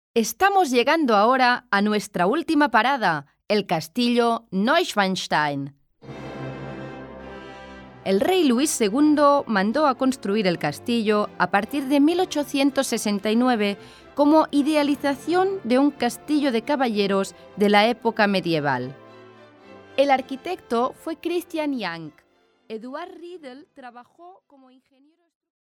Spanish (European)
Powerful effective audio tour commentary